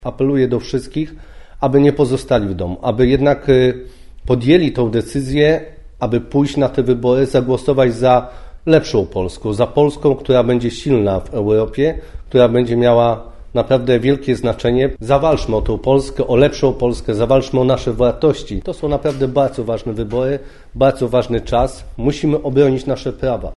Kampanię do Parlamentu Europejskiego podsumował dzisiaj kandydat z listy Prawa i Sprawiedliwości, poseł Wojciech Kossakowski. Podczas konferencji prasowej podziękował za wiele spotkań z wyborcami.